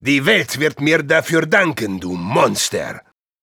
Voice file from Team Fortress 2 German version.
Spy_dominationpyro03_de.wav